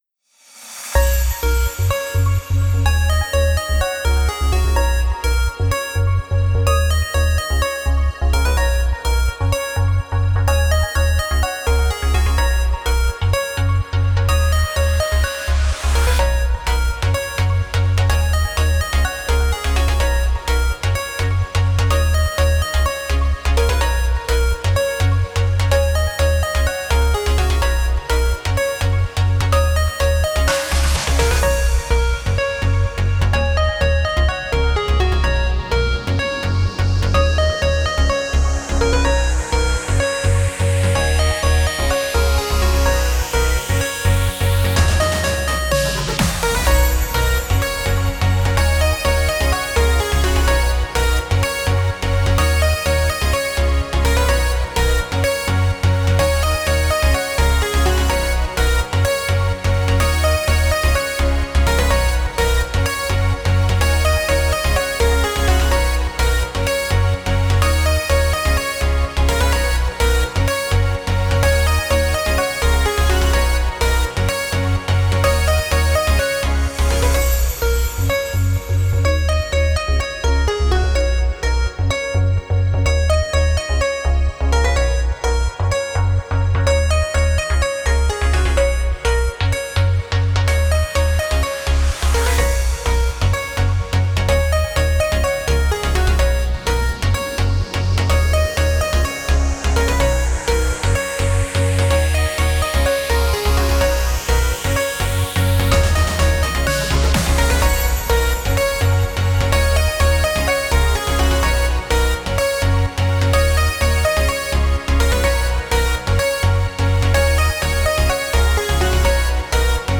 明るくかわいいEDM系BGMです。
※コチラは過去の作品を作り直した「リミックス版」です
▶ドラムなしバーション